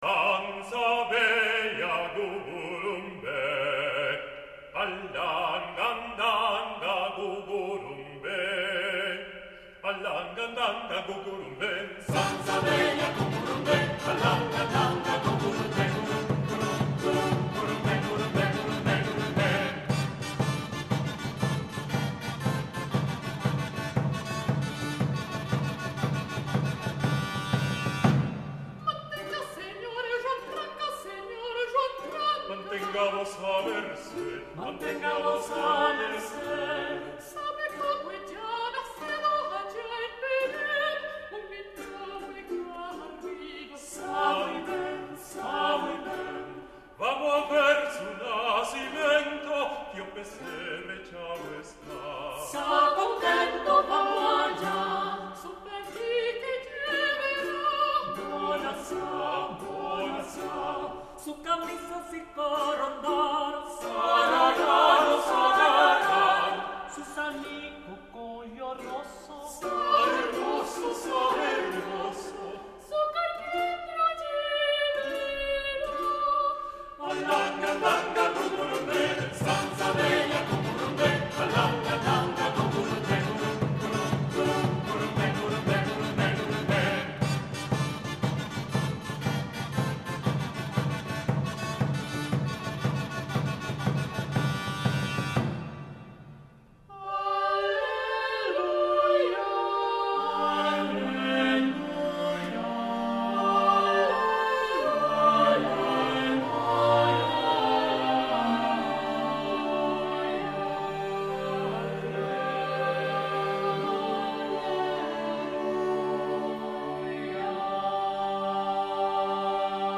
Les ensaladas es van fer molt populars en el segle XVI .Són cançons polifòniques,que,com el seu nom indica ,són una barreja de tota mena d’ingredients : religiosos o profans, homofonia o contrapunt, nombre de veus, elements còmics, èpics, seriosos o irònics.
També solen incloure generalment onomatopeies.
Aquí tens un fragment d’una ensalada de Mateu Fletxa el vell, compositor català del segle XVI. Porta per títol La Negrina.